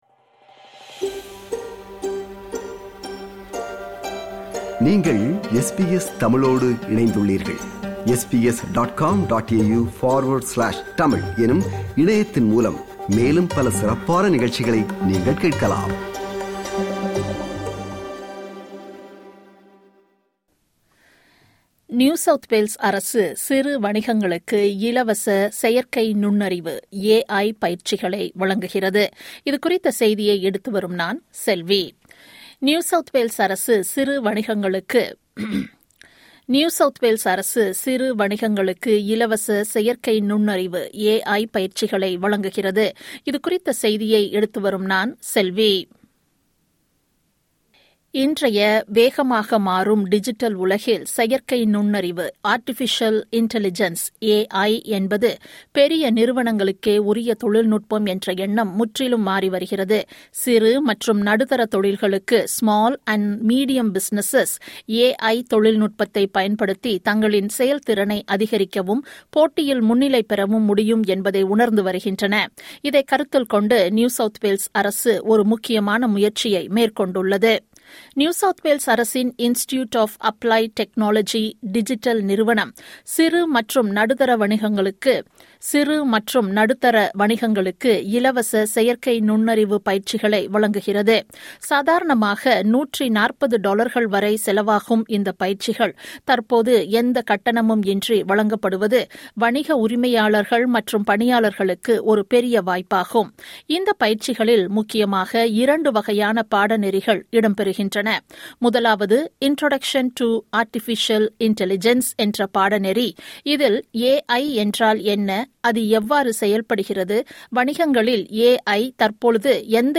NSW அரசு சிறு வணிகங்களுக்கு இலவச செயற்கை நுண்ணறிவு (AI) பயிற்சிகளை வழங்குகிறது. இது குறித்த செய்தியை எடுத்து வருகிறார்